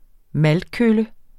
Udtale [ ˈmald- ]